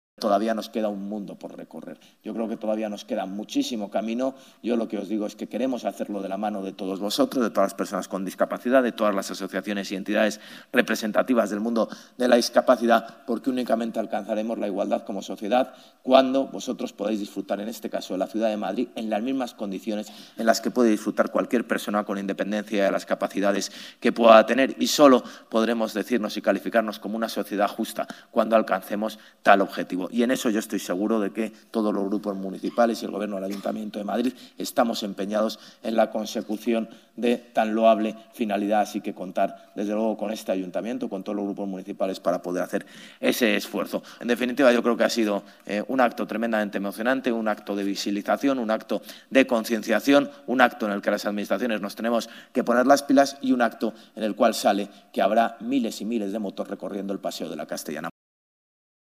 Durante el acto institucional celebrado por el Día Internacional de las Personas con Discapacidad
Nueva ventana:José Luis Martínez-Almeida, alcalde de Madrid